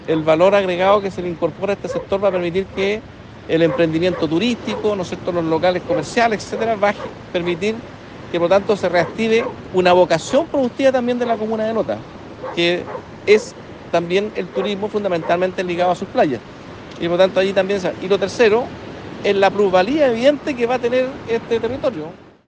Los trabajos terminarán a medidos del próximo mes y darán un impulso a esta tradicional playa, donde buscan seguir fomentando el turismo comentó el delegado Presidencial (s), Humberto Toro.